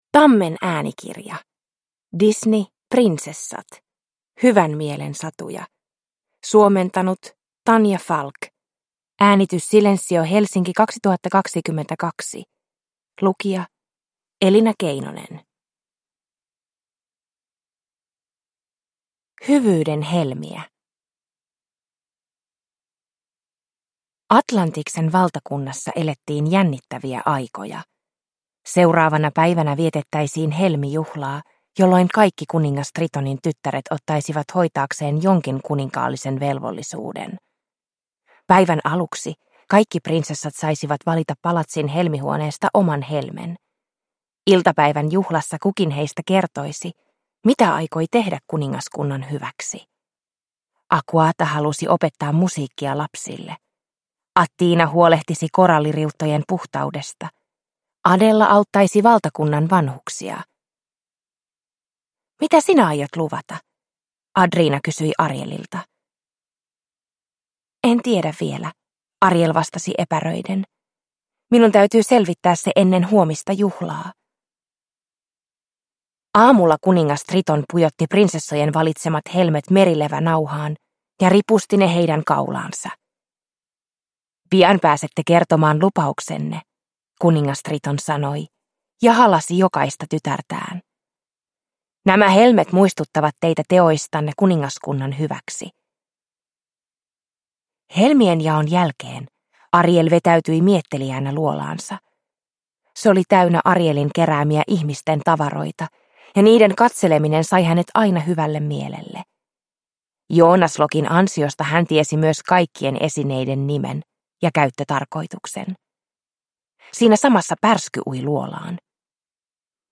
Disney. Prinsessat. Hyvän mielen satuja – Ljudbok – Laddas ner